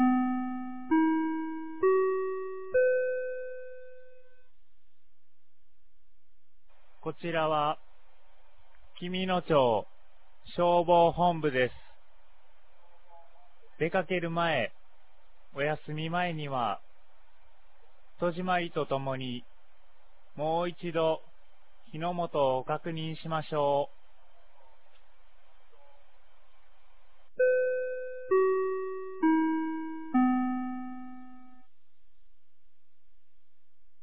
2023年09月16日 16時00分に、紀美野町より全地区へ放送がありました。
放送音声